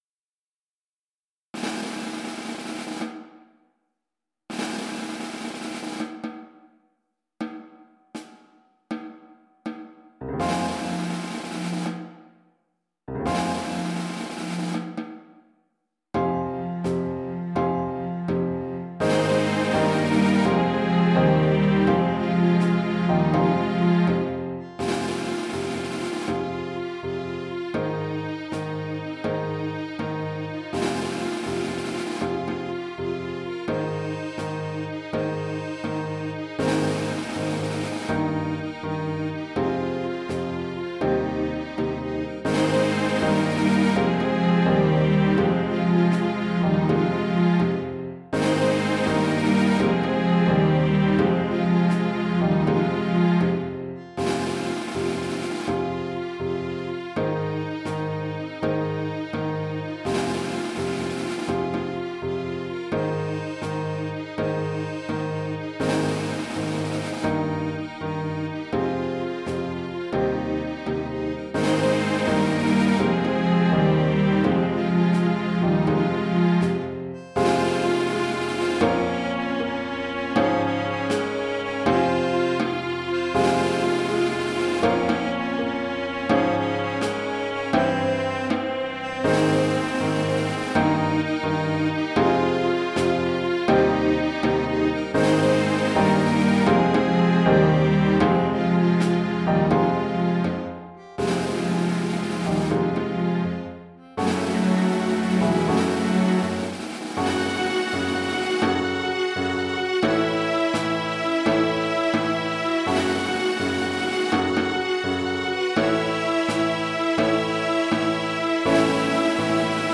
rehearsal recording